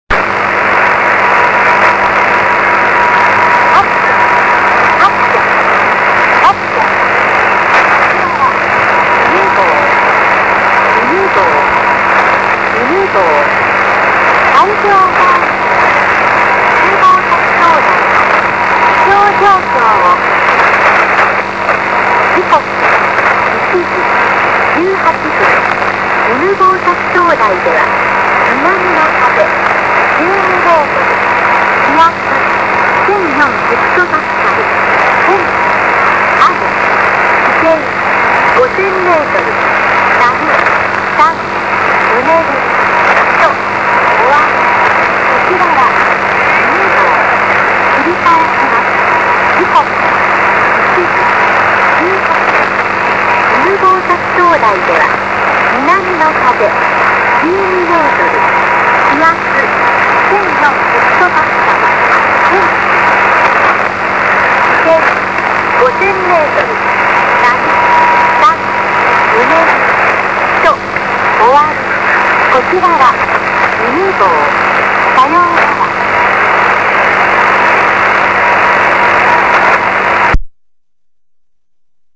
灯 台 放 送（海上保安丁・船舶気象通報）
一般のAM放送バンドからは微妙にずれていますので、普通のAMラジオでは受信できません。
（自作中波アンテナを使用）